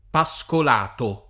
Pascolato [ pa S kol # to ] cogn.